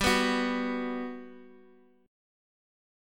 B5/G chord
B-5th-G-x,x,x,0,0,2.m4a